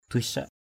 /d̪uɪ’s-saʔ/ 1.
duissak.mp3